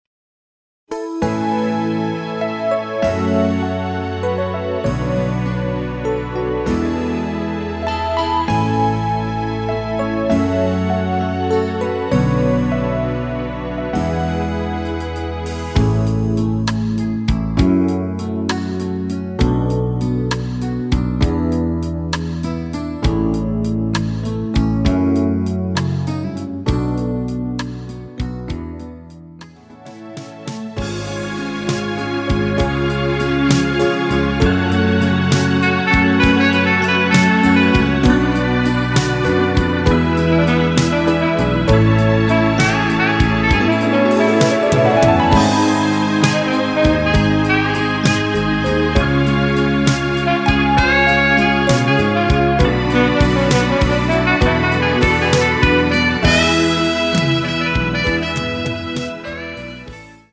키 A 가수
원곡의 보컬 목소리를 MR에 약하게 넣어서 제작한 MR이며